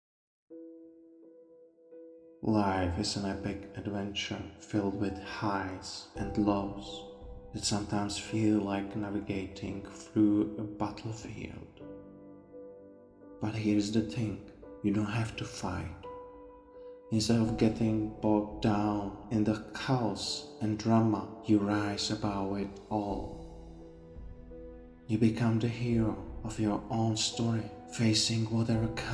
You Don’t Have to Fight is a short motivational speech that guides listeners to let go of internal conflict and find peace through acceptance and self-compassion.